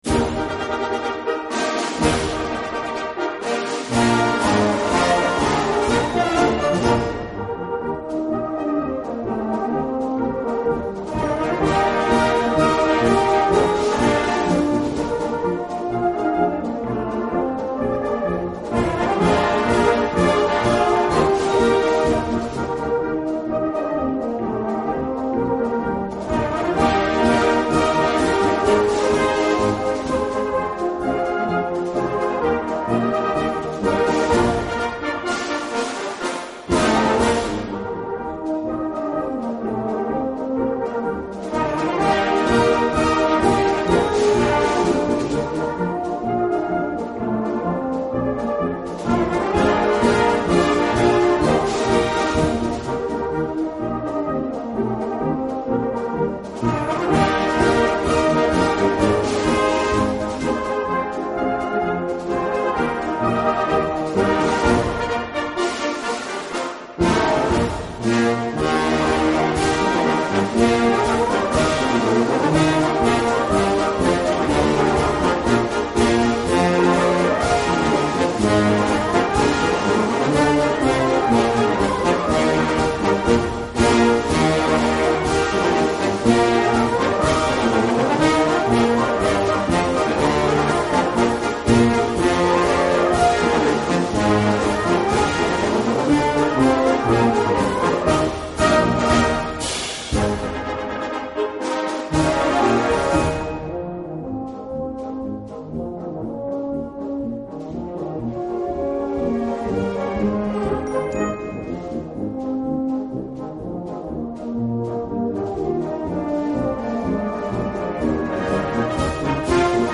Gattung: Marsch
19 x 14 cm Besetzung: Blasorchester Zu hören auf